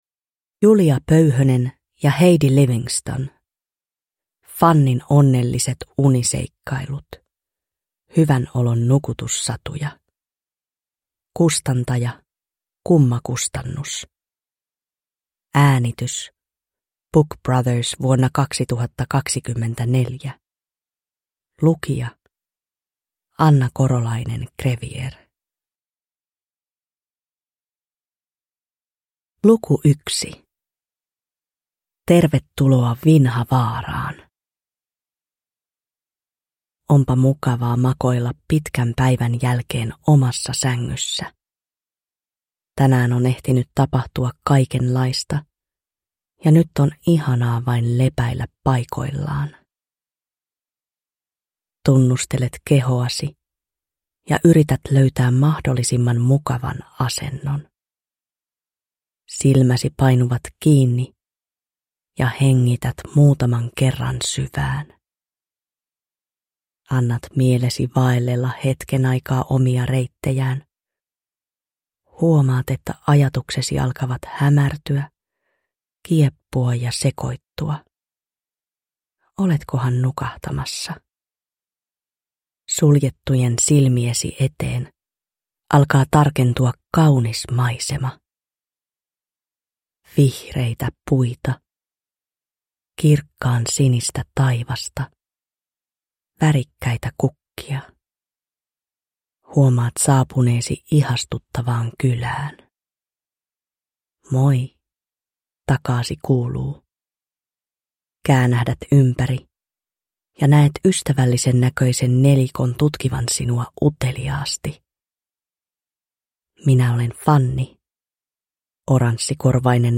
Fannin onnelliset uniseikkailut (ilman musiikkia) – Ljudbok